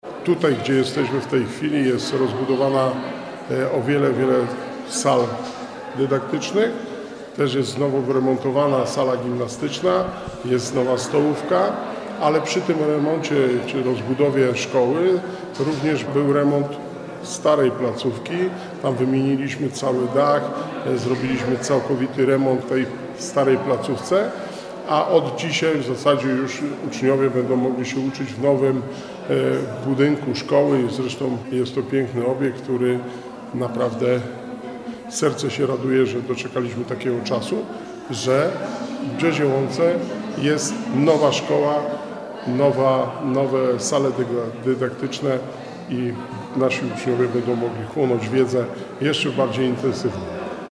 W piątek, 22 marca odbyło się oficjalne otwarcie.
– Rozbudowa szkoły była potrzebna dla mieszkańców, bo rozwój gminy jest intensywny – mówi Wojciech Błoński, wójt gminy Długołęka.